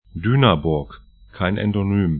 Dünaburg 'dy:nabʊrk Daugavpils 'daugafpɪls lv Stadt / town 55°53'N, 26°32'E